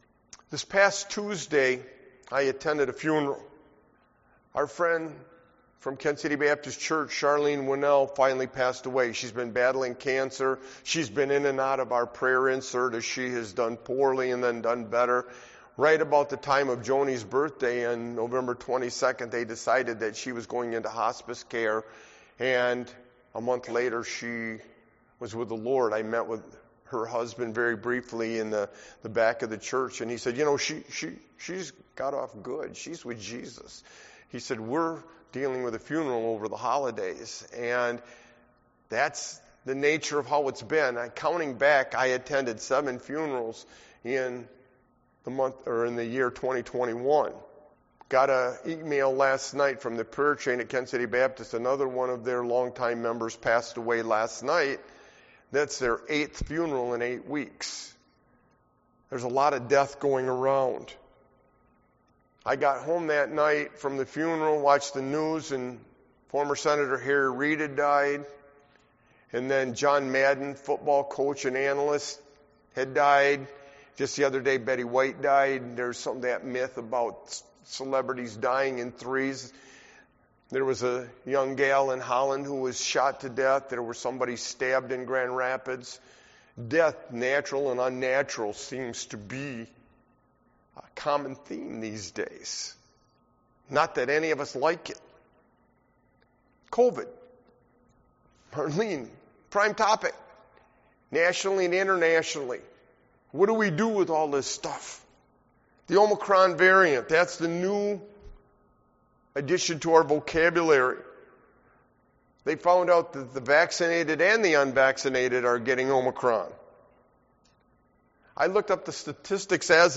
Sermon-The-Uphill-Nature-of-the-Christian-walk-1222.mp3